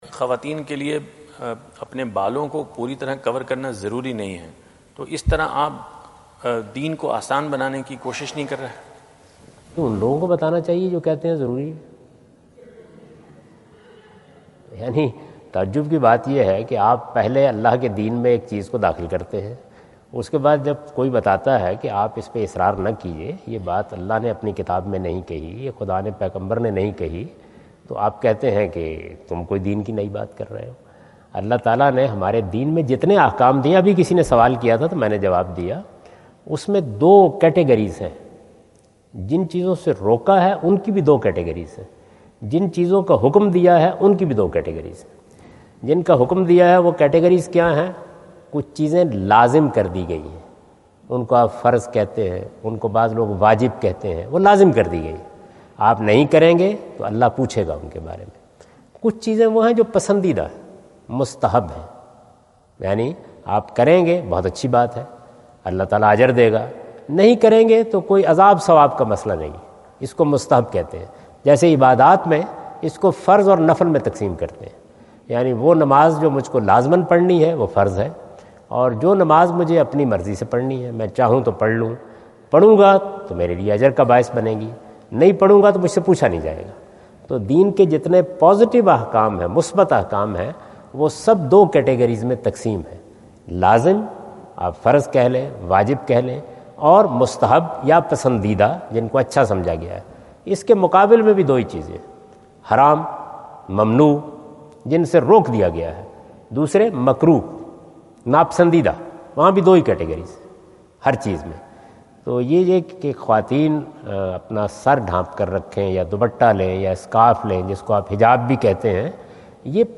Javed Ahmad Ghamidi answer the question about "Are not you making religion easy regarding veil laws?" During his US visit at Wentz Concert Hall, Chicago on September 23,2017.
جاوید احمد غامدی اپنے دورہ امریکہ2017 کے دوران شکاگو میں "پردے سے متعلق کیا آپ دین کو آسان نہیں بنا رہے؟" سے متعلق ایک سوال کا جواب دے رہے ہیں۔